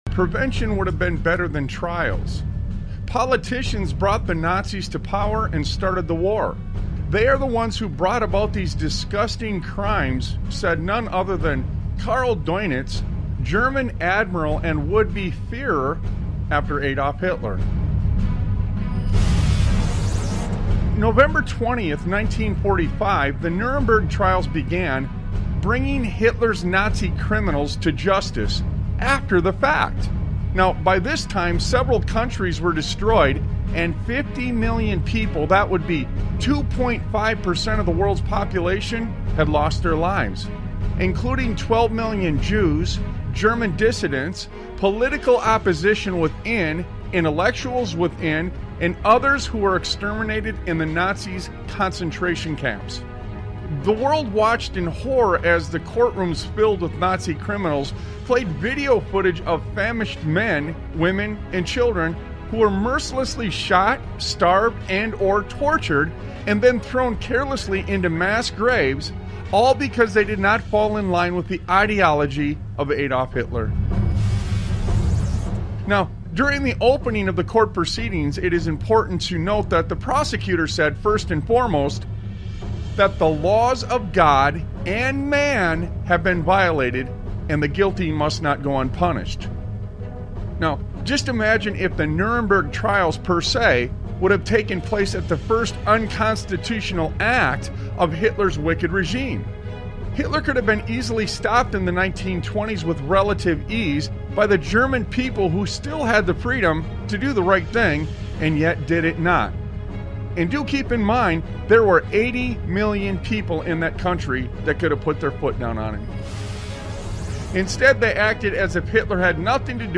Talk Show Episode, Audio Podcast, Sons of Liberty Radio and They Want You To Join In With The Deception on , show guests , about They Want You To Join In With The Deception, categorized as Education,History,Military,News,Politics & Government,Religion,Christianity,Society and Culture,Theory & Conspiracy